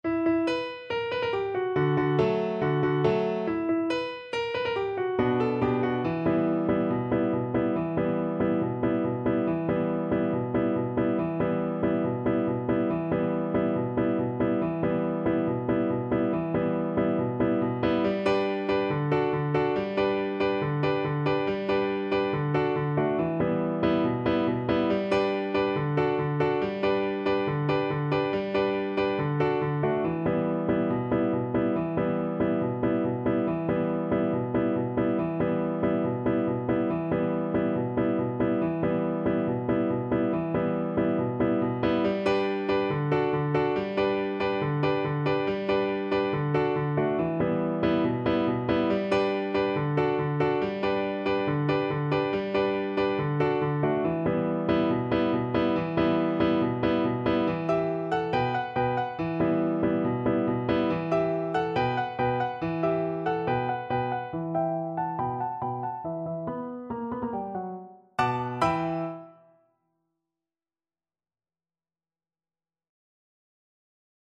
Free Sheet music for Viola
ViolaViola
Traditional Music of unknown author.
E minor (Sounding Pitch) (View more E minor Music for Viola )
Very Fast =c.140
4/4 (View more 4/4 Music)